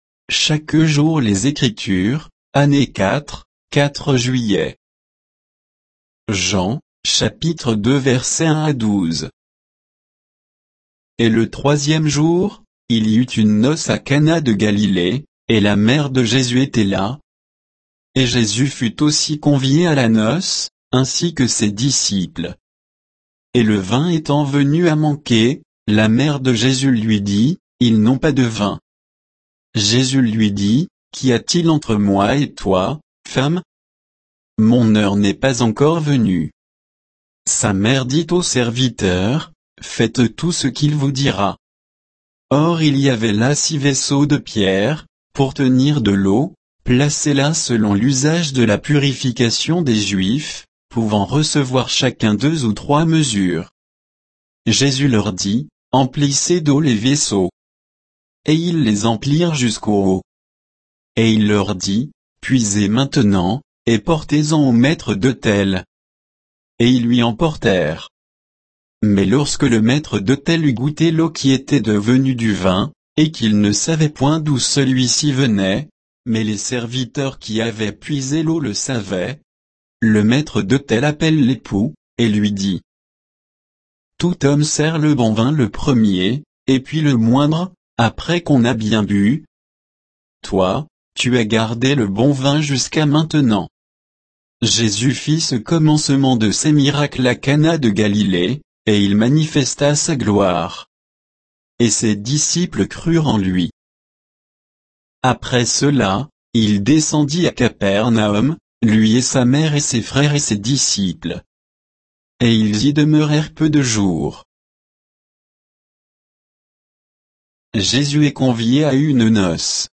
Méditation quoditienne de Chaque jour les Écritures sur Jean 2, 1 à 12